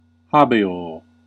Ääntäminen
IPA: /hɑː/